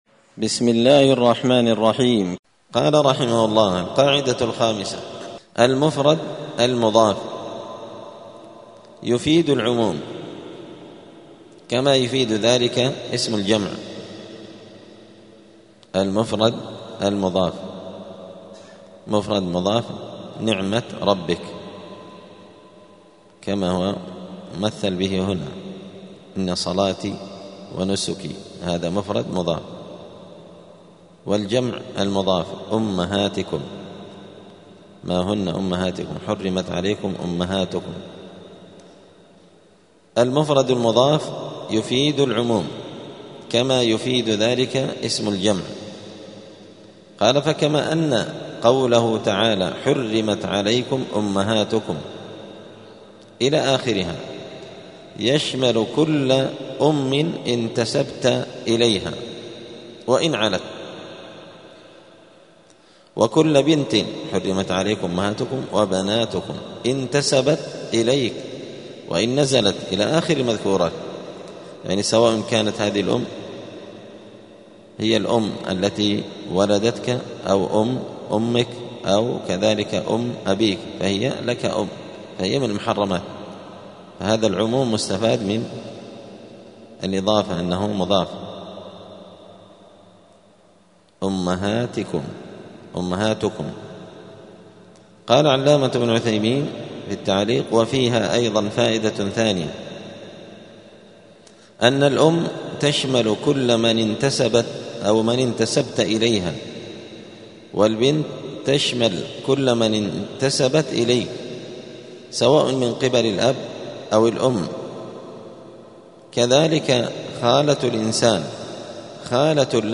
دار الحديث السلفية بمسجد الفرقان قشن المهرة اليمن
6الدرس-السادس-من-كتاب-القواعد-الحسان.mp3